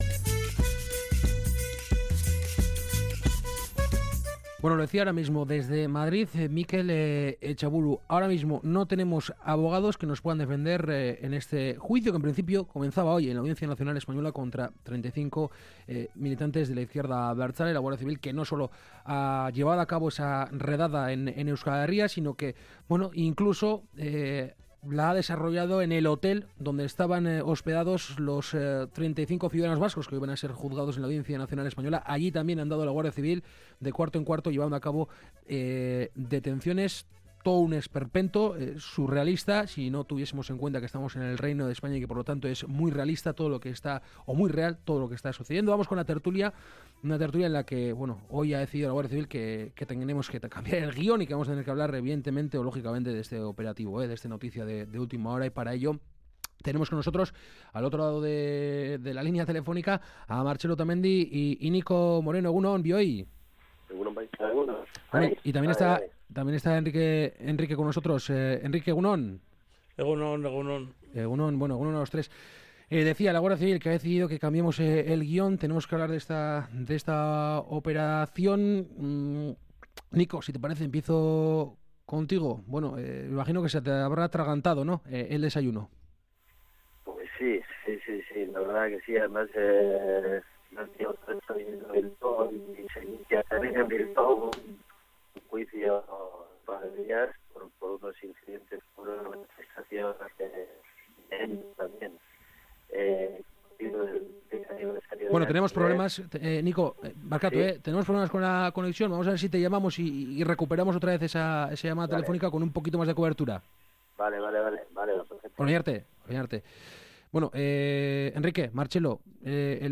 La tertulia de Kalegorrian
Charlamos y debatimos sobre algunas de las noticias más comentadas de la semana con nuestros colaboradores habituales.